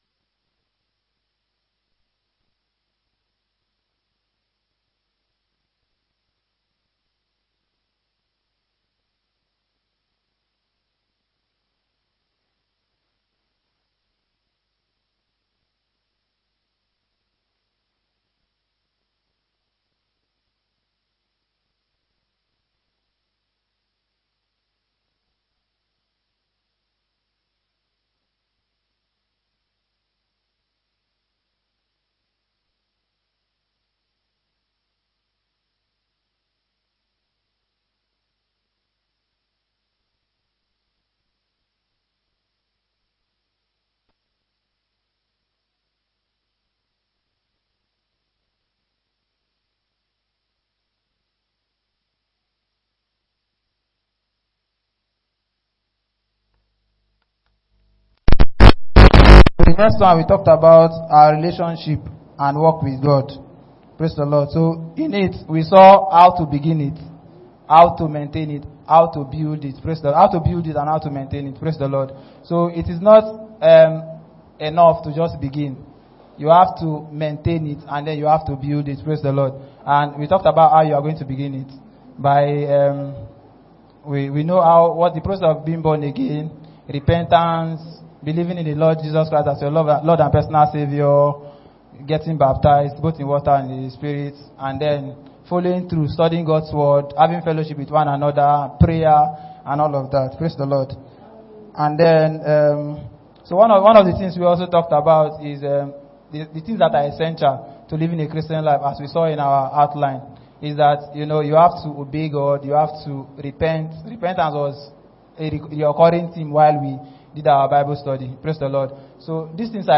Exhortation on Doubt
Morning Prayers